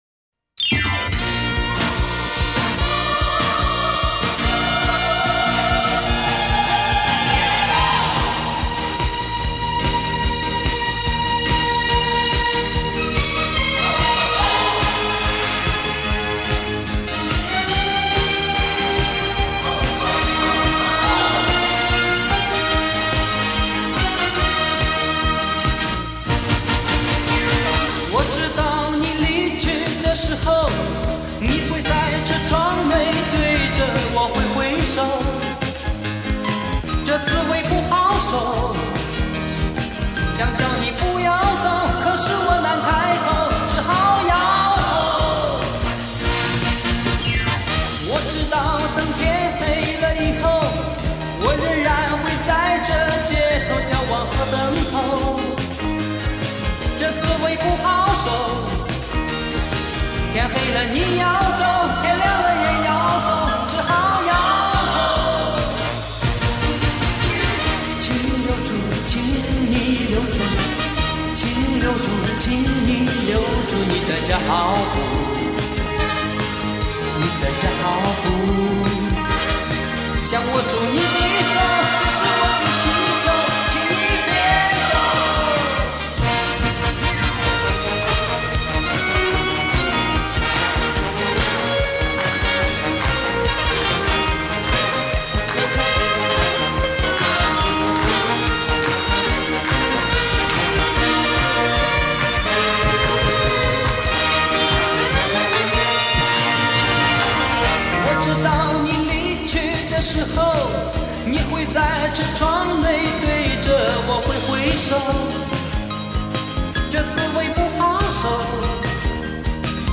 用现代语言来形容当时的这张专辑全是“口水歌”呵呵，